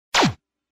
Звуки телевизора
На этой странице собраны разнообразные звуки телевизора: от характерного писка при включении до статичных помех и переключения каналов.